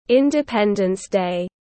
Ngày quốc khánh tiếng anh gọi là Independence Day, phiên âm tiếng anh đọc là /ˌɪn.dɪˈpen.dəns deɪ/